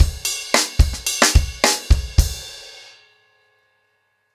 Framework-110BPM_1.7.wav